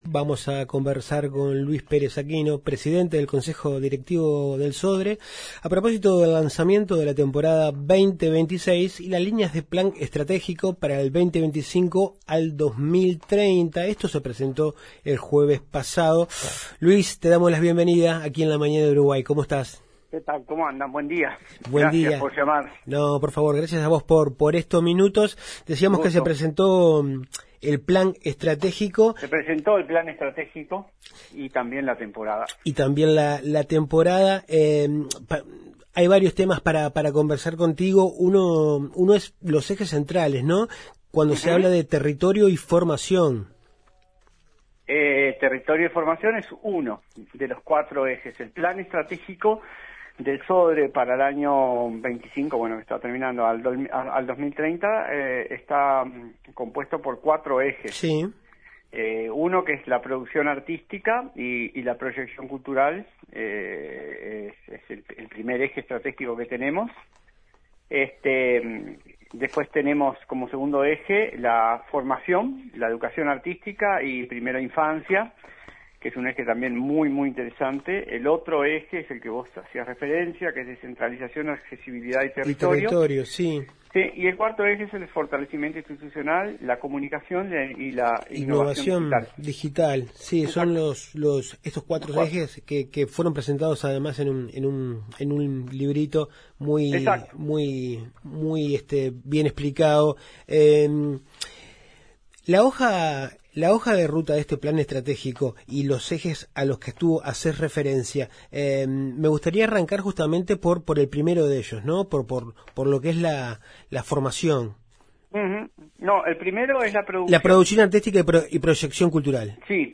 Entrevista a Luis Pérez Aquino, presidente del Consejo Directivo del Sodre